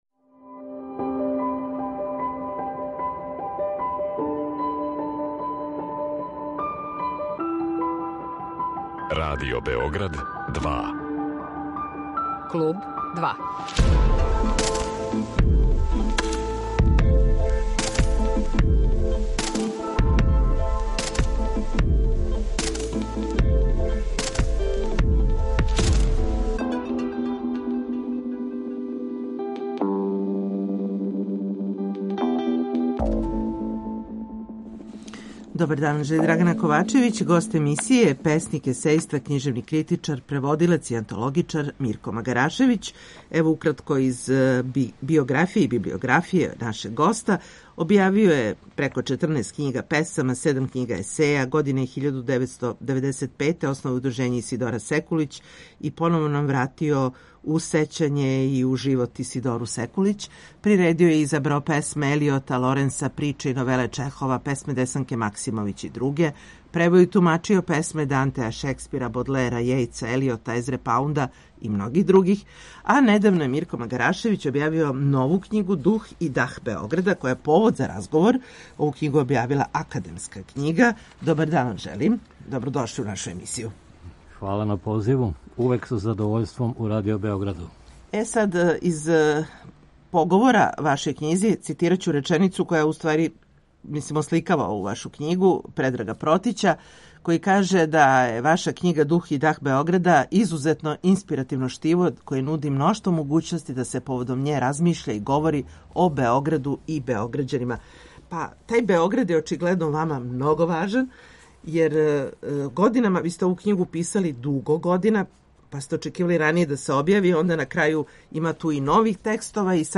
Гост емисије је песник, есејиста, књижевни критичар, преводилац и антологичар